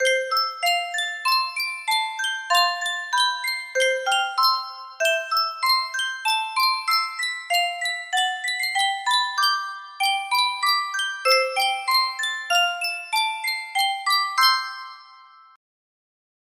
Yunsheng Music Box - Angels from the Realms of Glory 2020 music box melody
Full range 60